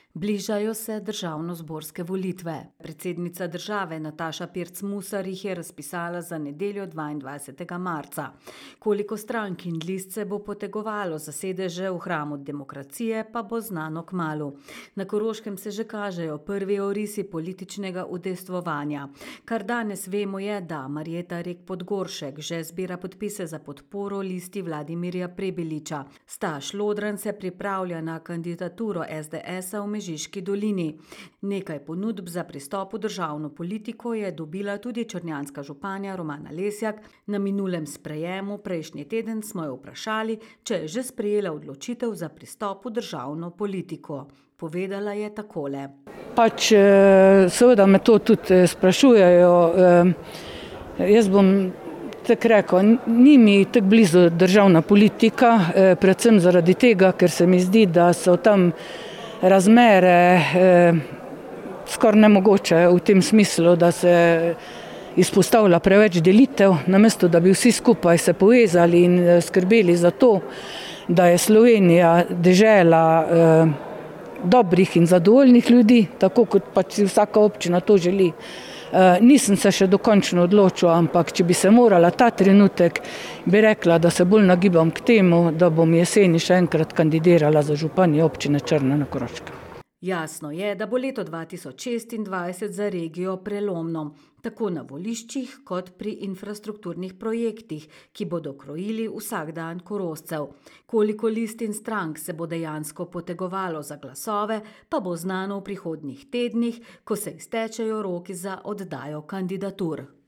Na minulem sprejemu prejšnji teden smo jo vprašali, če je že sprejela odločitev za pristop v državno politiko.